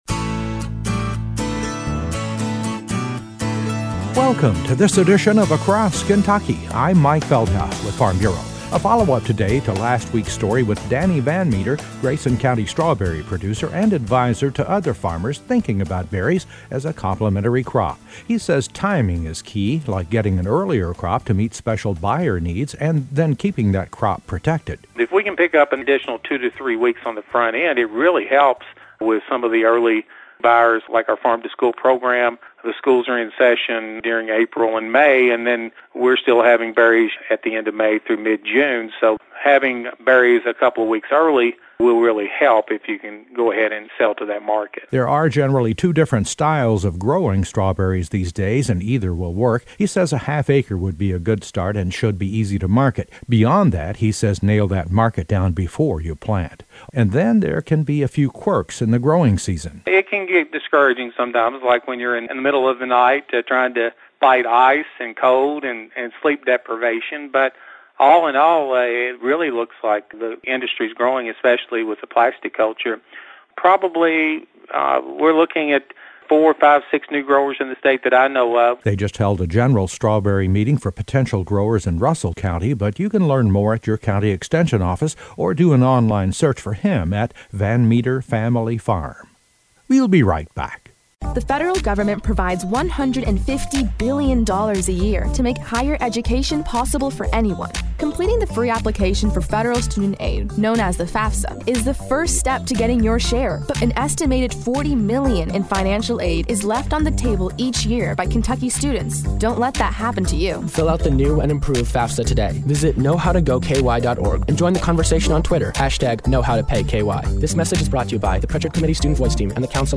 A feature story